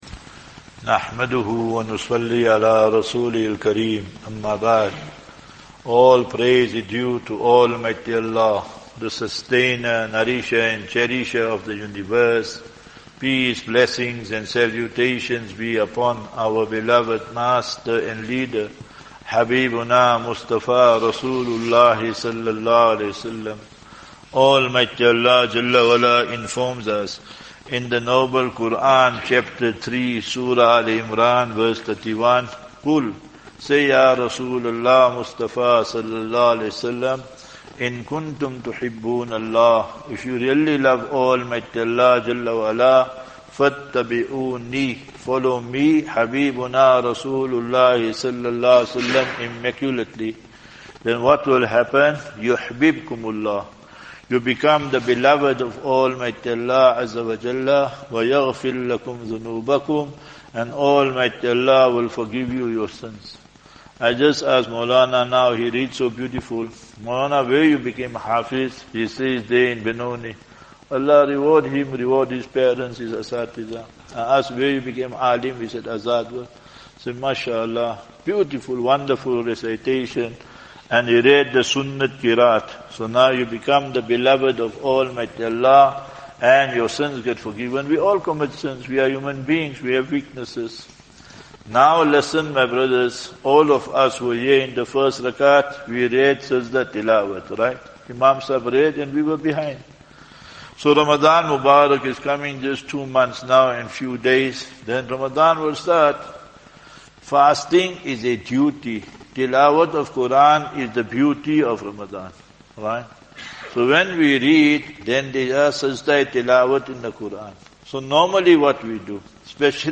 Morning Discourse
at Masjid Tariq bin Ziyad